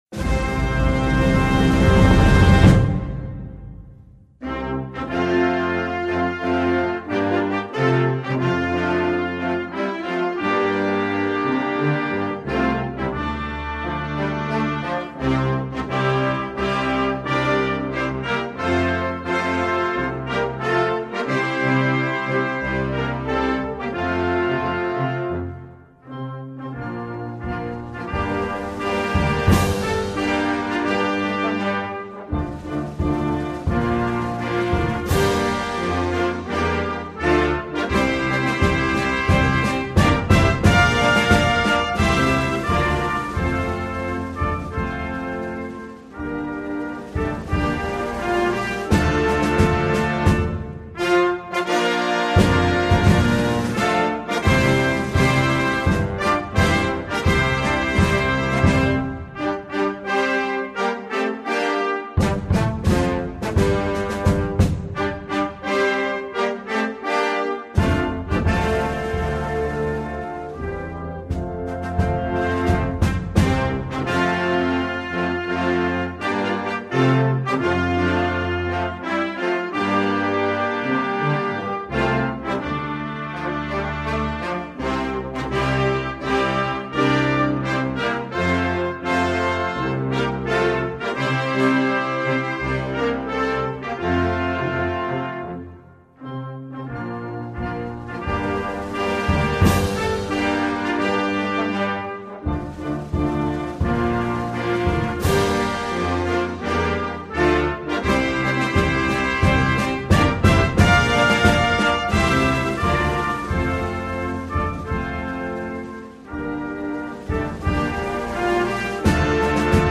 National_Anthem_of_Uzbekistan_(Instrumental).mp3